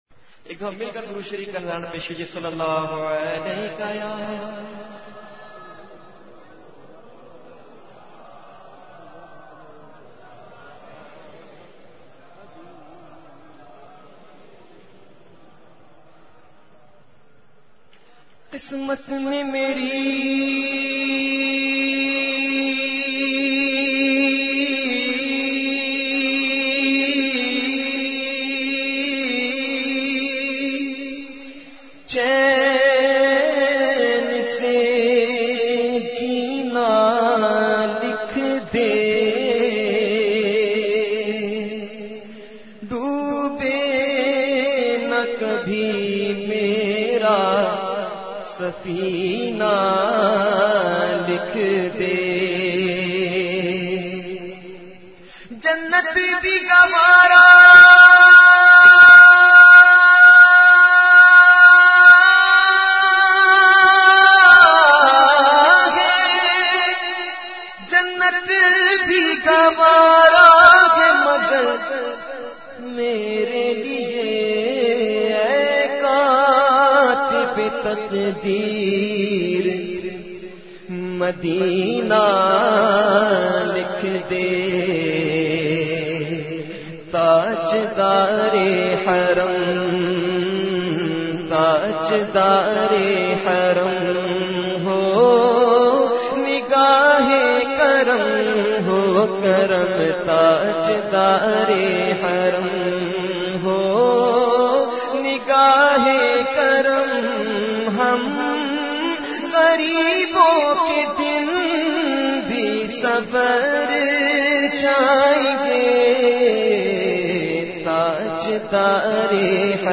Naat Sharif
recited by famous Naat Khawan of Pakistan